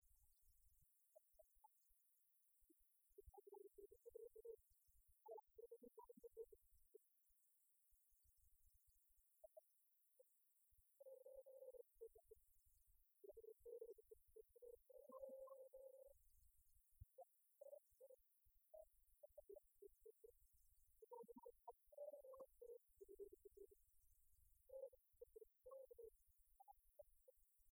Genre strophique
Concert de la chorale des retraités